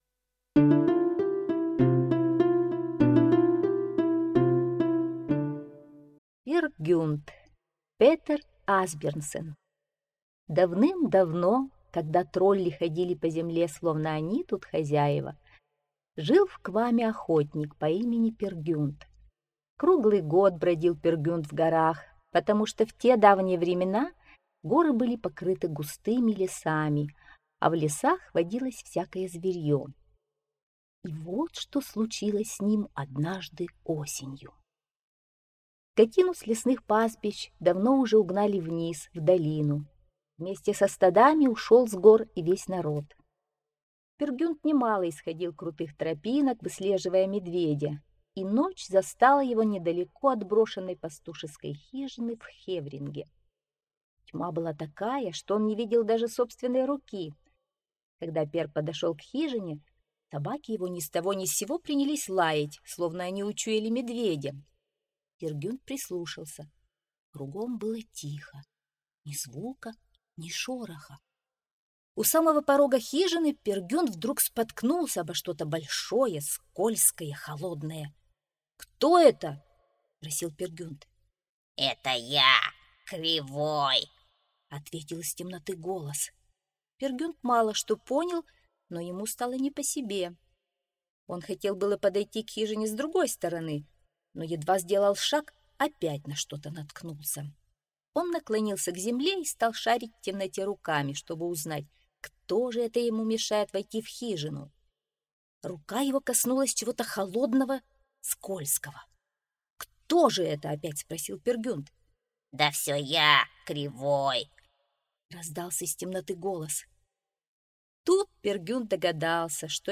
Пер Гюнт - аудиосказка Асбьернсен - слушать онлайн